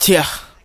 Vox
Yuh(1).wav